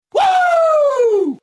Громкий крик Вуууу - Wooooo!